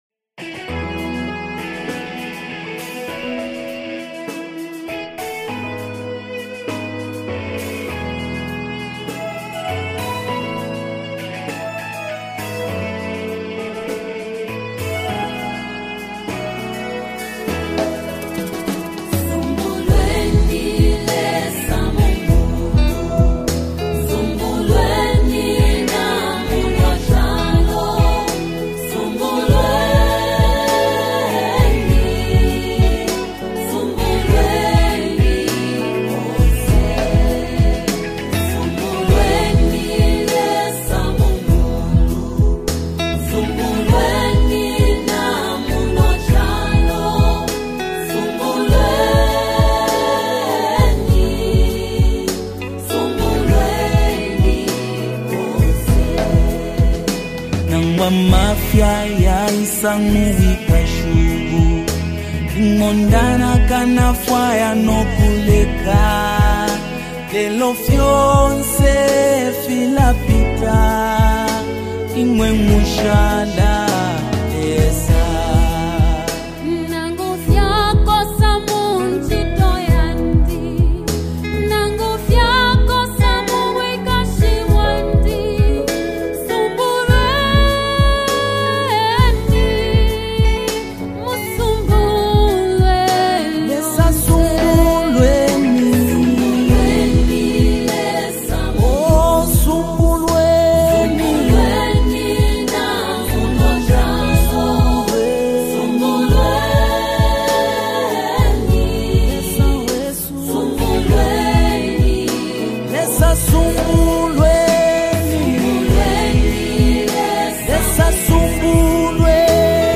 LATEST ZAMBIAN WORSHIP SONG 2025